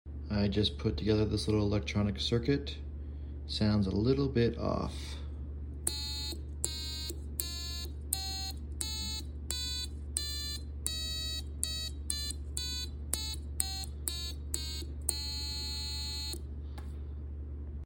My DIY piano circuit sounds sound effects free download
My DIY piano circuit sounds a bit off.